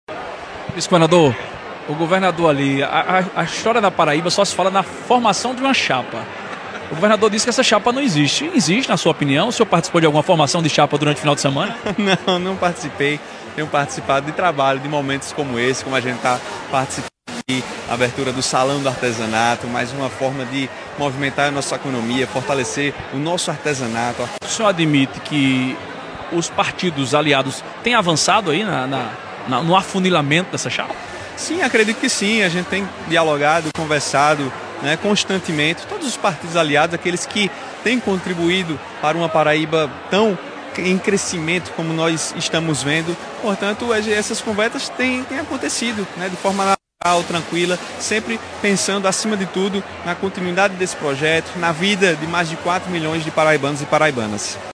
Na noite desta quarta-feira (11), o vice-governador da Paraíba, Lucas Ribeiro, marcou presença na abertura oficial do 40º Salão de Artesanato da Paraíba, realizado em Campina Grande.
Questionado pela imprensa sobre as movimentações políticas para as eleições de 2026, Lucas negou que exista, até o momento, uma chapa formalizada dentro do grupo governista. No entanto, ele reconheceu que as conversas entre os partidos da base aliada estão se intensificando.